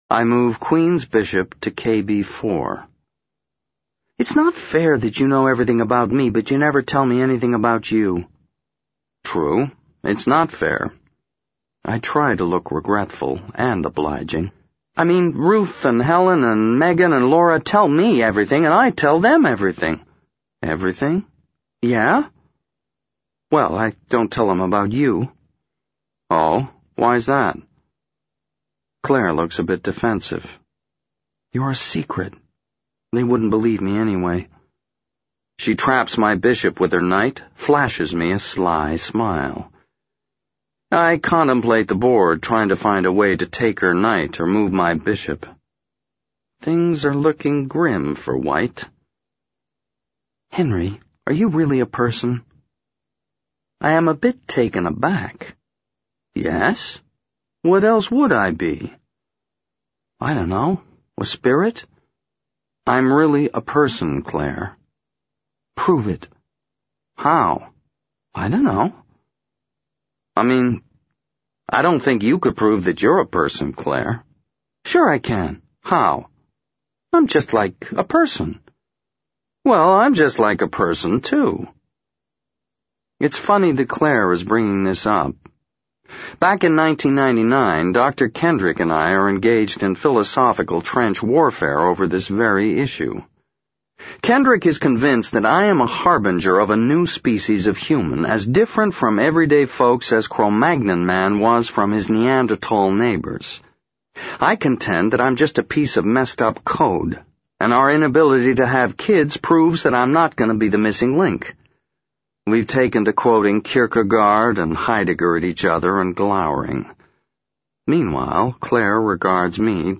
在线英语听力室【时间旅行者的妻子】59的听力文件下载,时间旅行者的妻子—双语有声读物—英语听力—听力教程—在线英语听力室